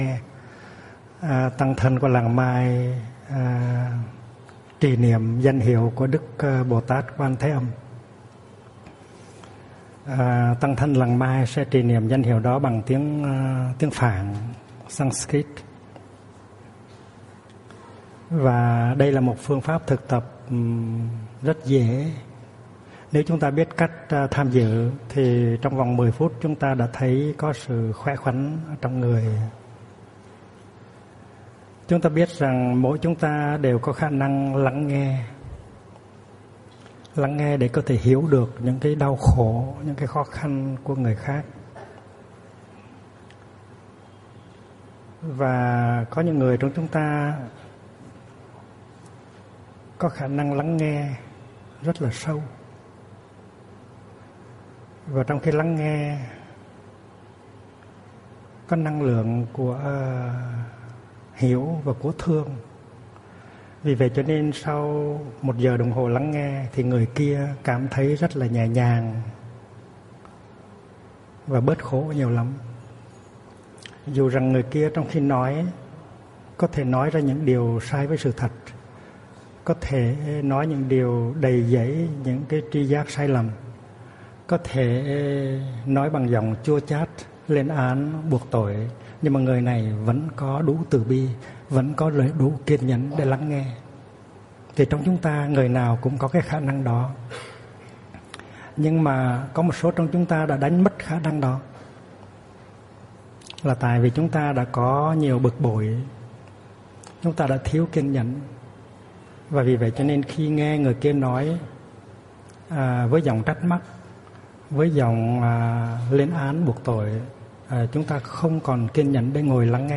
Mp3 thuyết pháp Tương lai đạo Bụt ở Âu, Úc và Mỹ châu 2 do HT. Thích Nhất Hạnh giảng tại Trung Tâm Khoa Học Xã Hội TP. HCM ngày 04 tháng 02 năm 2005